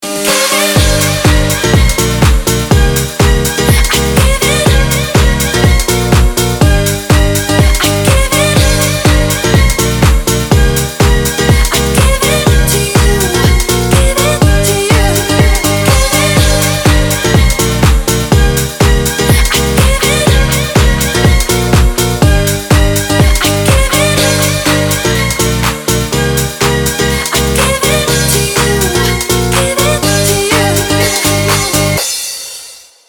• Качество: 256, Stereo
Disco House Remix